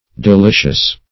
Delicious \De*li"cious\, a. [OF. delicieus, F. d['e]licieux, L.